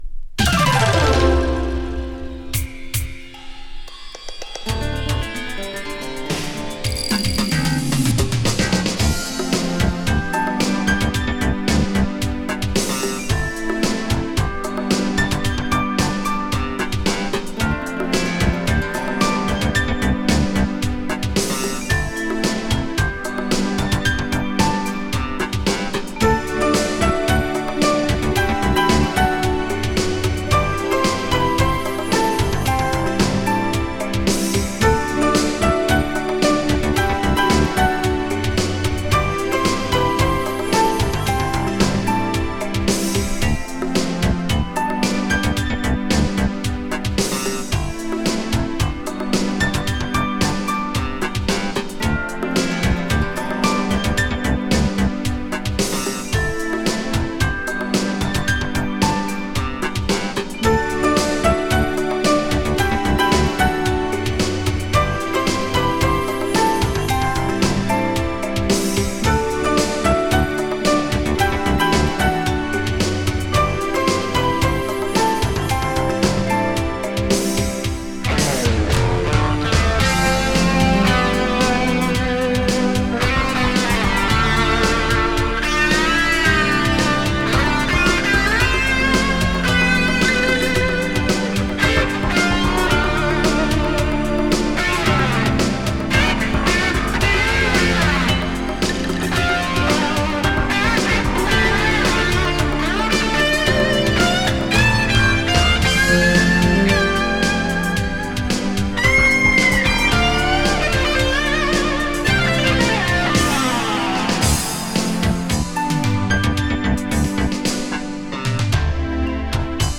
Electronics New Age!
[NEW AGE] [ELECTRONICS]